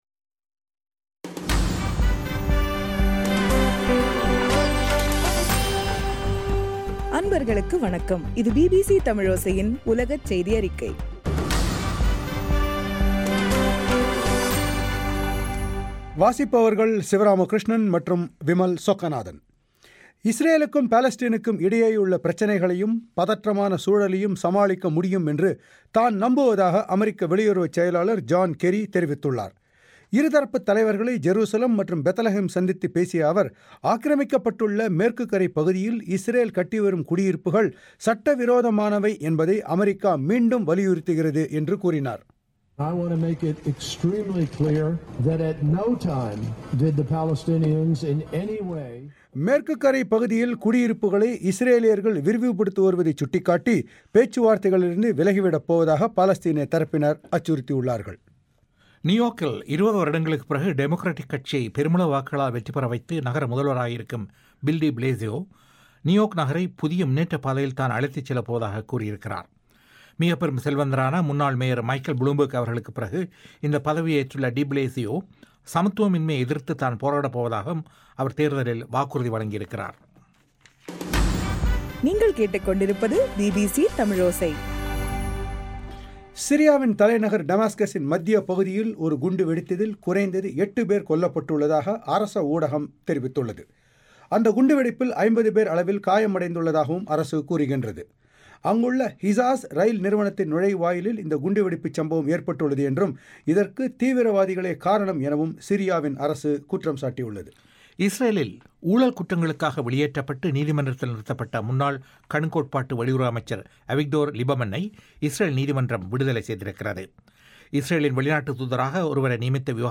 நவம்பர் 6 தமிழோசை உலகச் செய்திகள்